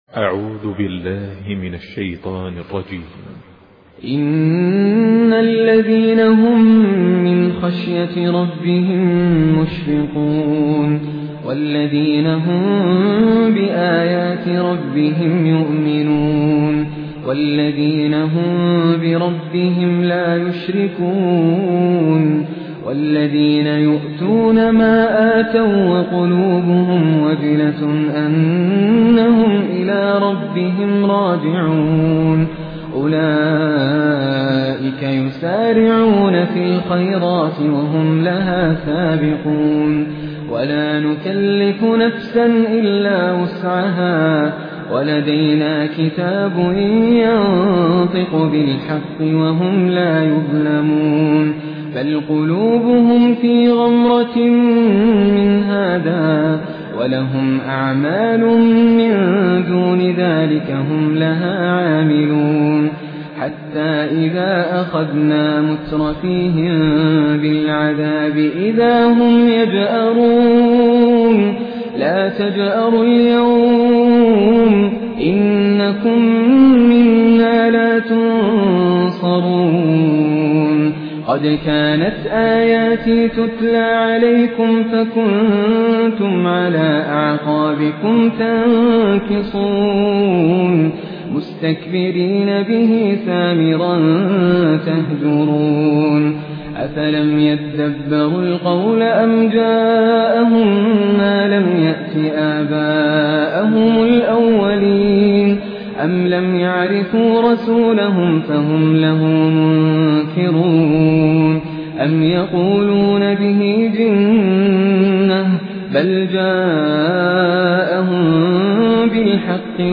Humble, distinctive recitations